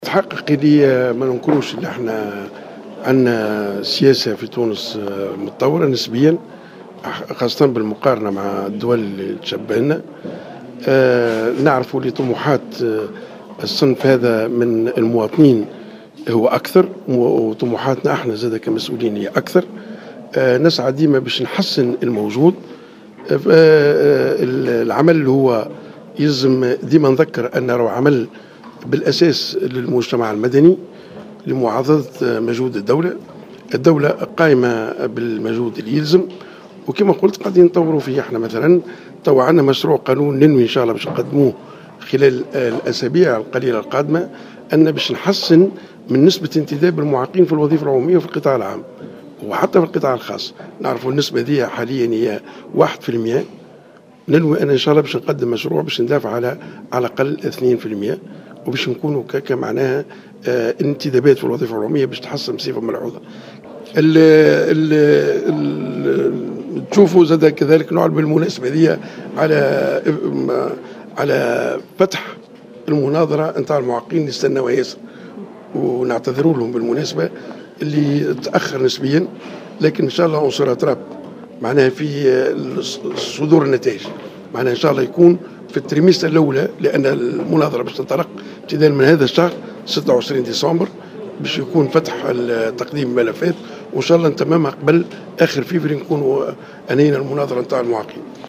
Le ministre des affaires sociales Ammar Younbai a annoncé sur les ondes de Jawhara FM ce mercredi 3 décembre 2014, l’ouverture d’un concours pour le recrutement des personnes handicapées.